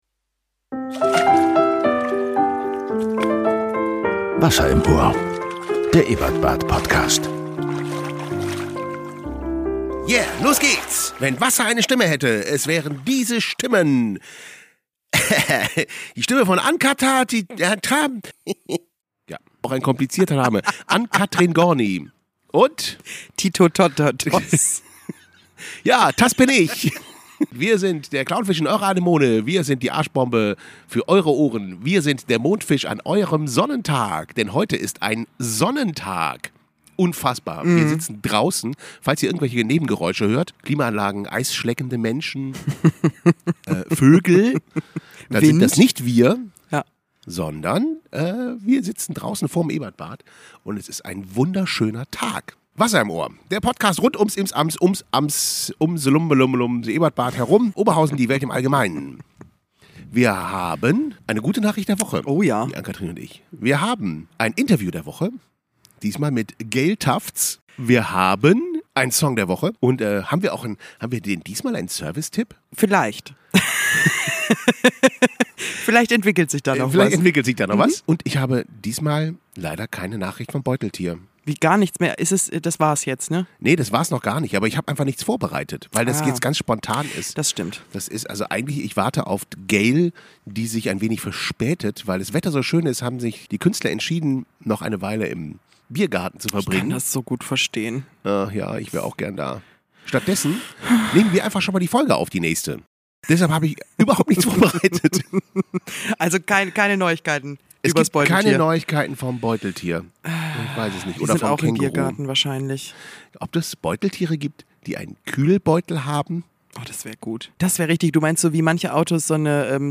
Im Interview: Gayle Tufts Die erste Außenaufnahme. Was sich der Mensch von der Seekuh abgeschaut hat. Ukulele als Waffe.